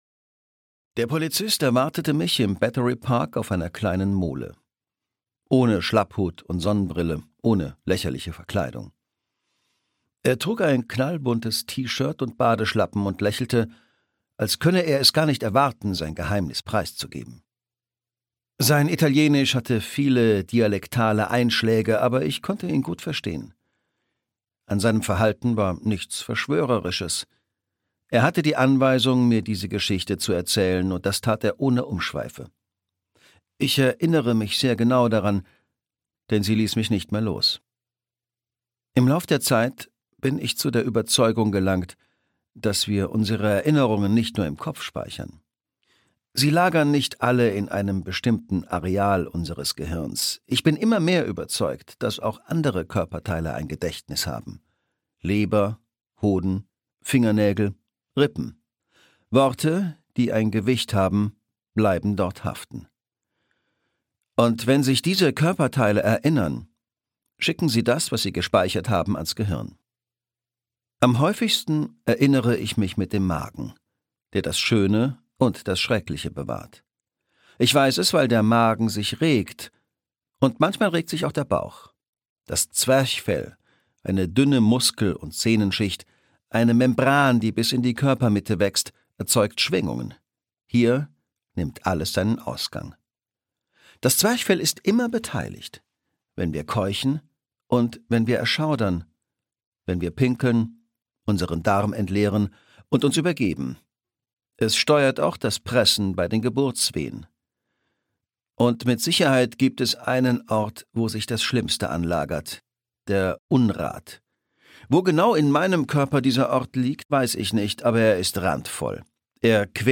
ZeroZeroZero - Roberto Saviano - Hörbuch